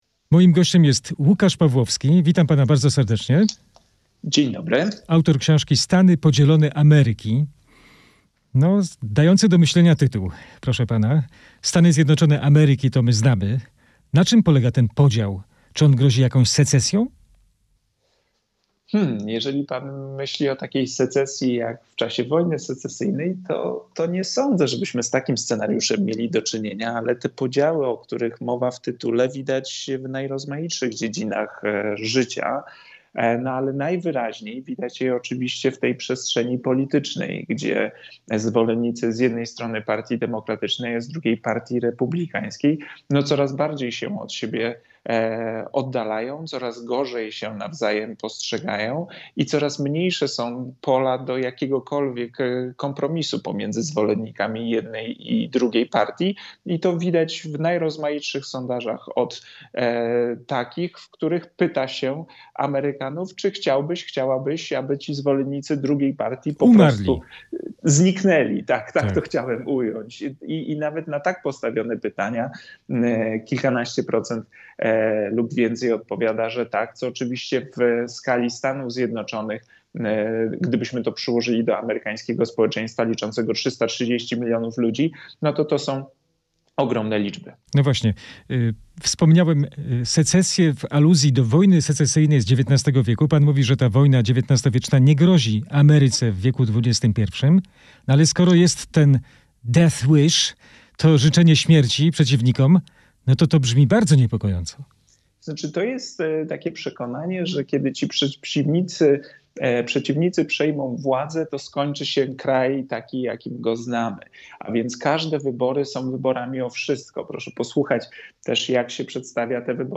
"Stany podzielone Ameryki" - rozmowa